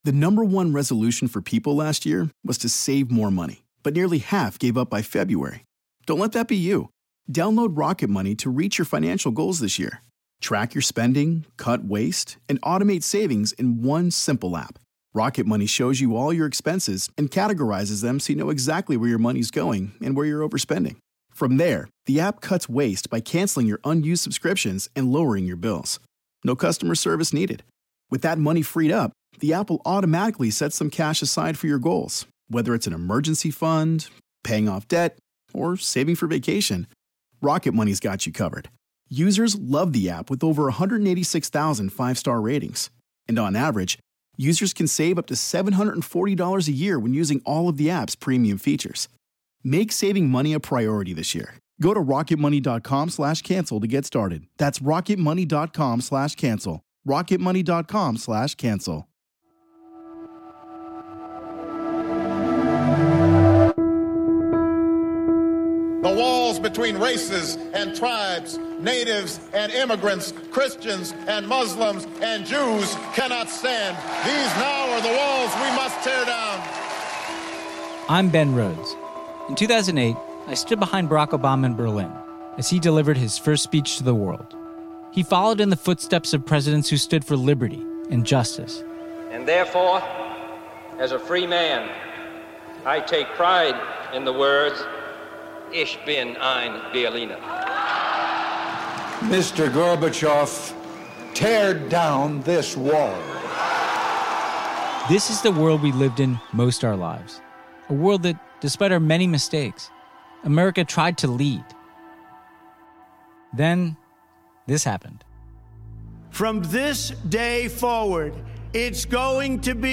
Missing America is the story of what happens when the United States, under Trump, abdicates our role as an example for the world. Host and former Deputy National Security Advisor to President Obama Ben Rhodes speaks to inspiring leaders and activists who are fighting to take up the slack in America’s absence, in a world where nationalism, authoritarianism, and disinformation have taken hold like never before.